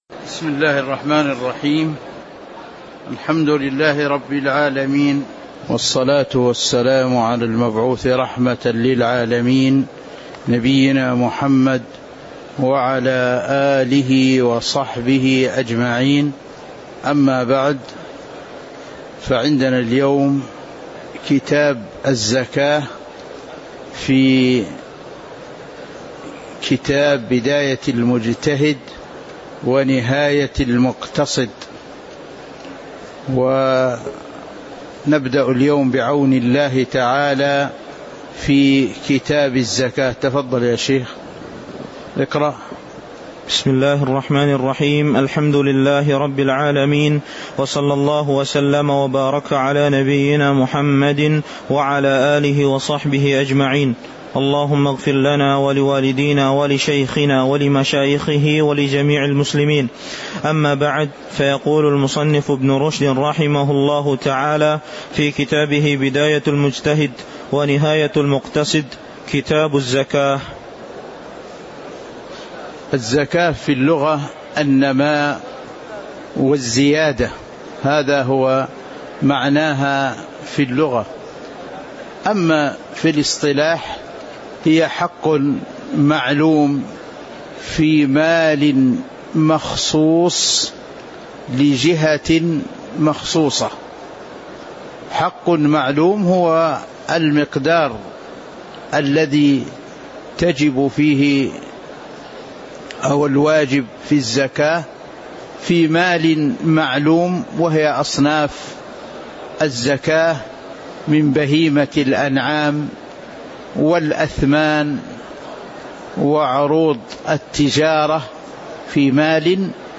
تاريخ النشر ١٦ ربيع الأول ١٤٤٥ هـ المكان: المسجد النبوي الشيخ